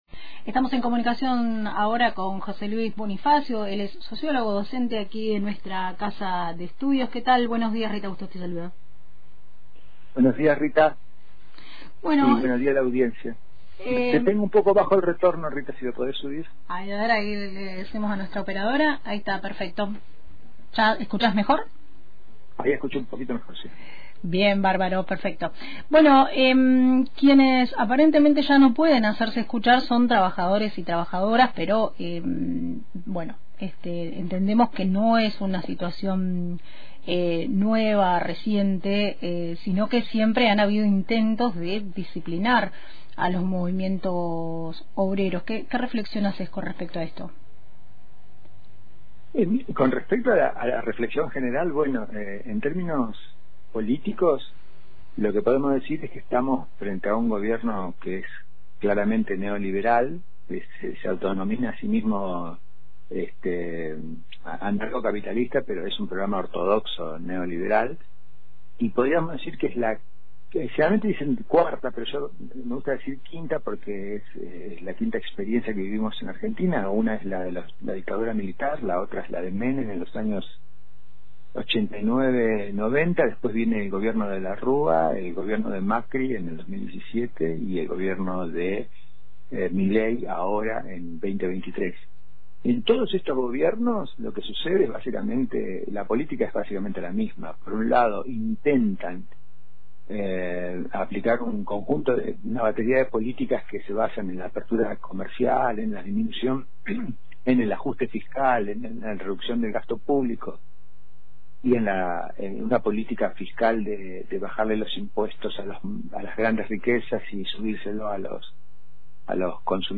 En entrevista con Radio Antena Libre